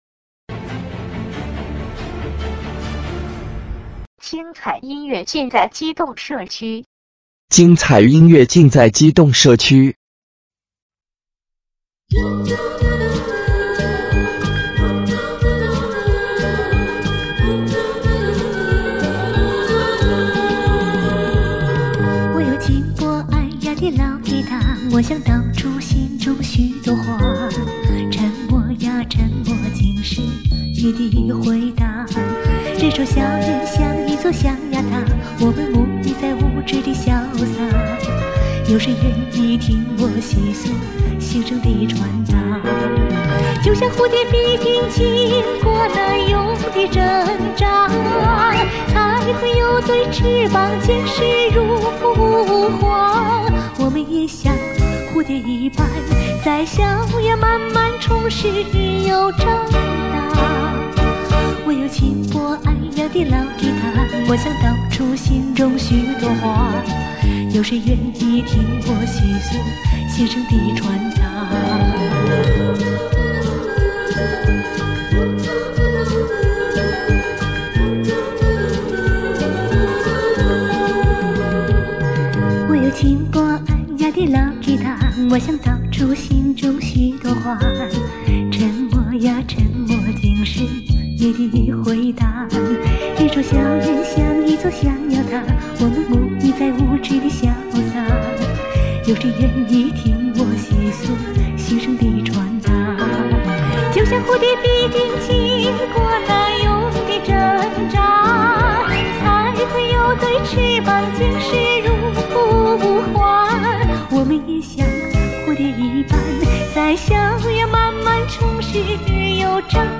她的演唱重于感情的表达，对歌曲的处理细腻、传神。在唱法上，把通俗与民族唱法融为一体，给人一种清新的感觉。